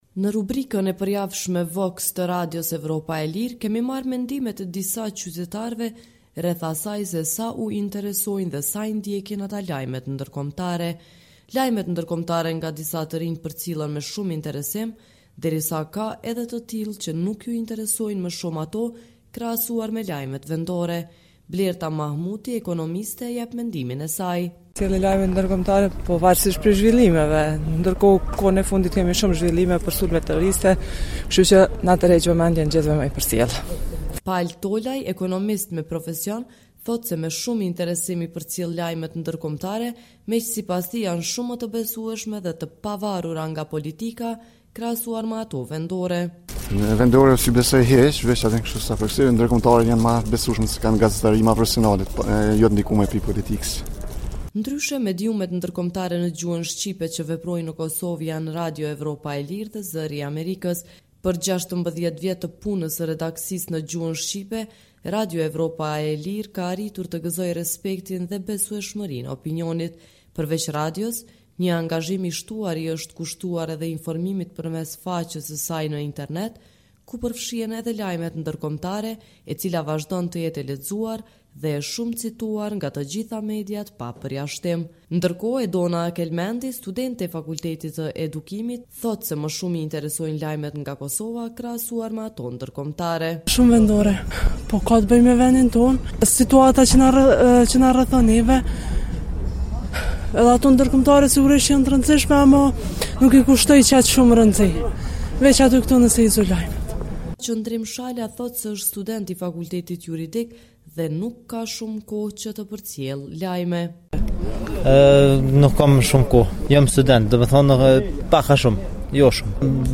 Në rubrikën e përjavshme “VOX”, të Radios Evropa e Lirë, kemi marrë mendimet e disa të rinjve rreth asaj se sa u interesojnë dhe sa i ndjekin ata lajmet ndërkombëtare.